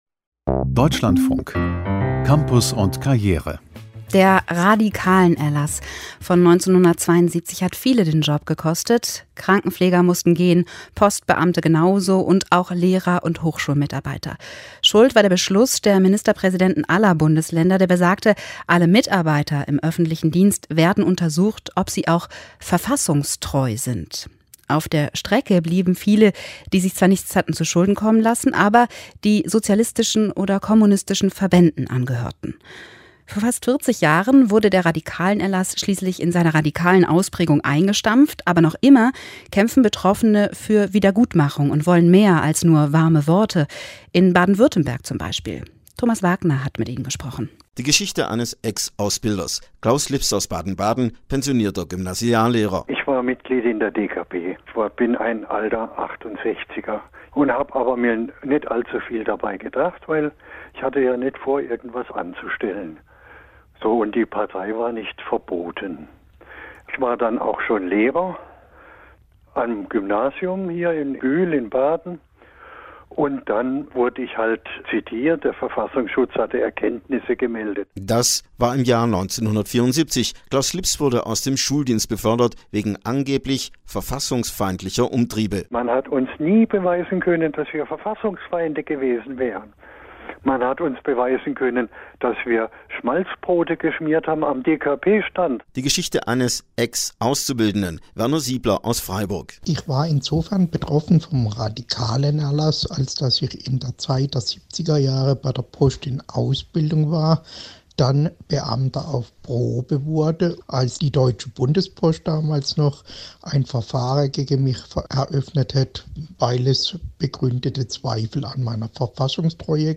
Am Tag der Menschenrechte Montag 10. Dezember 2018 fand auf dem Stuttgarter Schloßplatz vor dem Cafe Künstlerbund eine Kundgebung statt.
Bericht des Deutschlandfunk